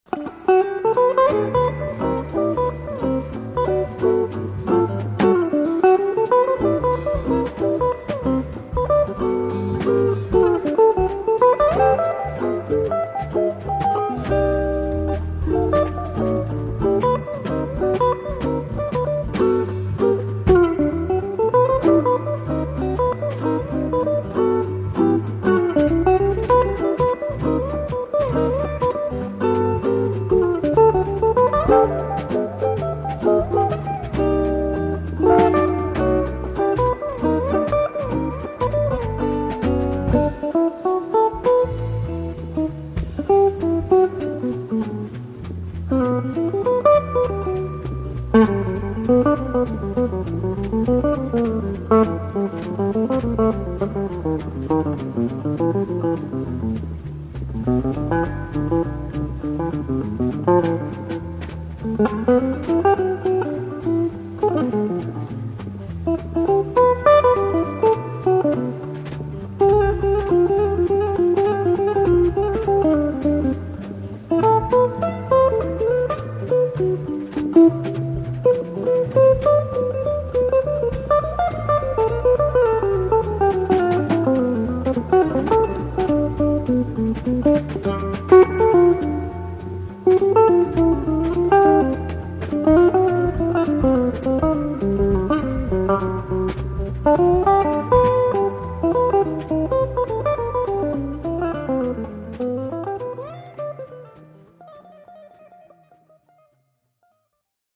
JAZZ GUITAR
Jazz Trio of guitar, upright bass and drums